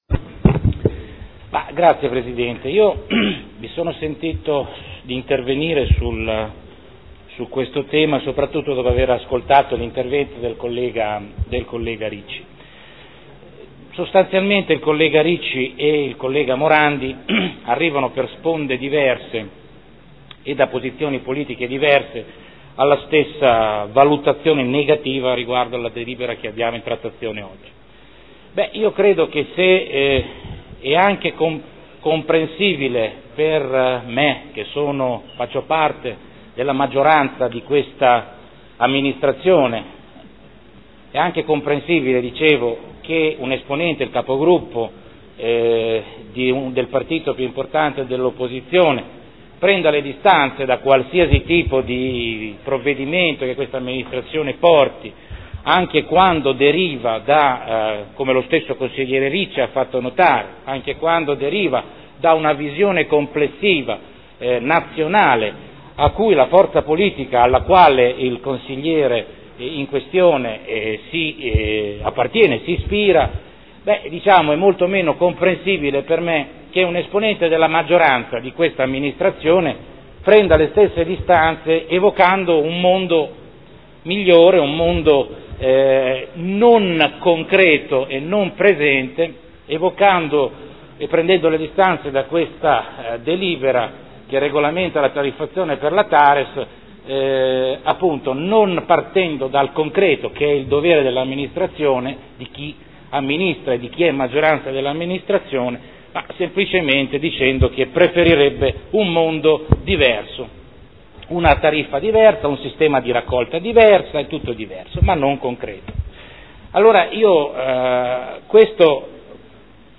Seduta del 24/06/2013. Dichiarazione di voto. Delibera: Tributo comunale sui rifiuti e sui servizi indivisibili – TARES – Approvazione delle tariffe, del Piano Economico Finanziario, del Piano annuale delle attività per l’espletamento dei servizi di gestione dei rifiuti urbani e assimilati